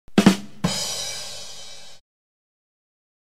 Ba Dum Tss!.mp3